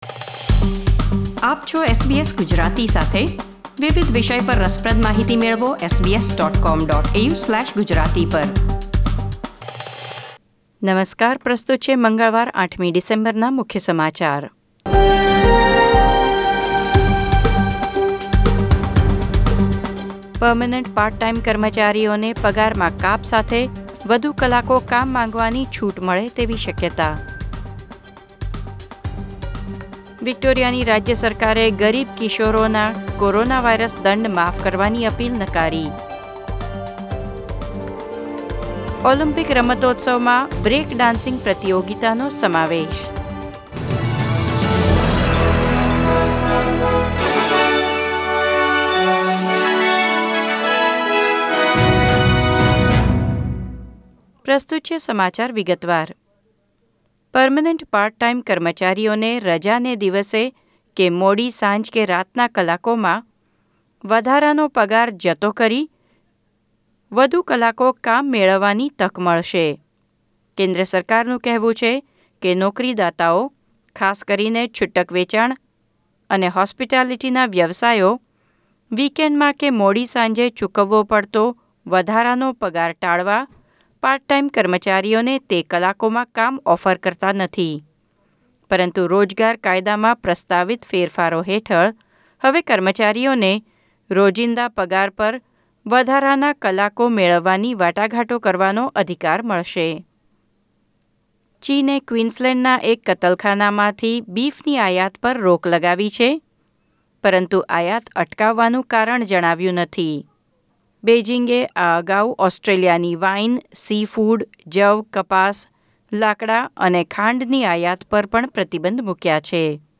SBS Gujarati News Bulletin 8 December 2020